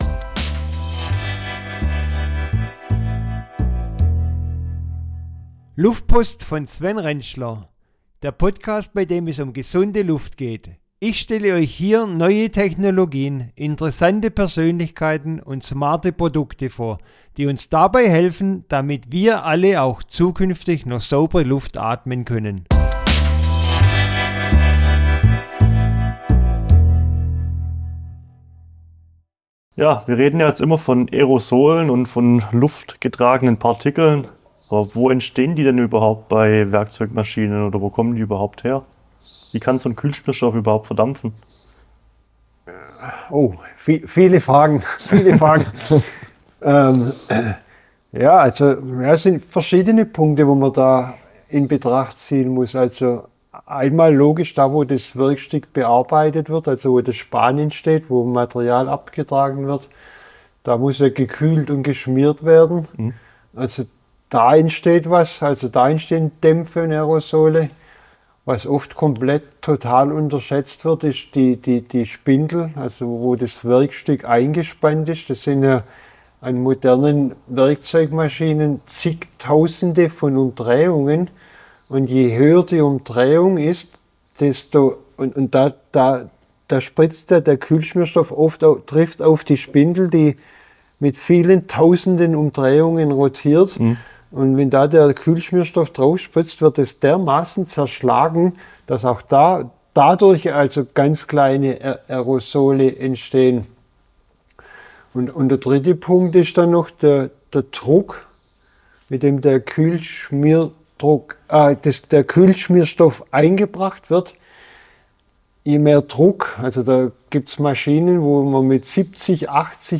Ein Expertentalk